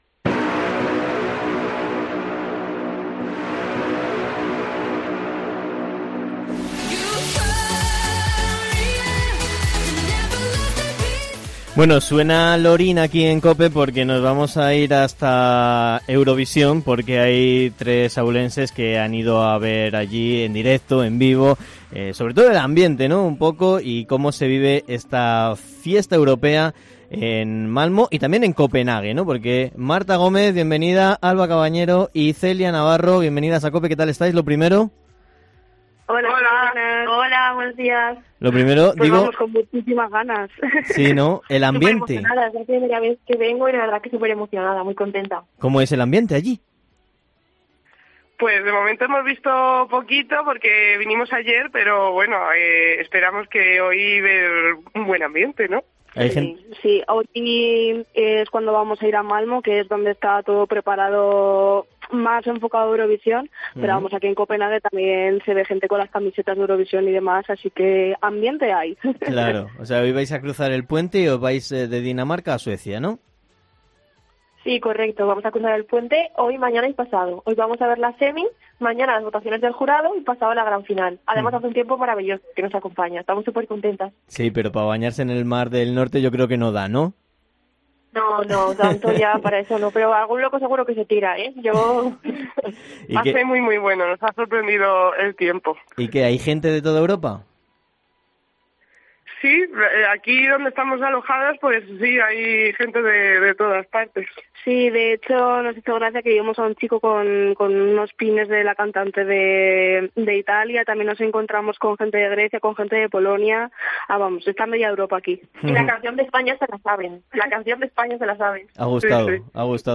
Entrevista en COPE Ávila con tres jóvenes de Ávila desde Eurovisión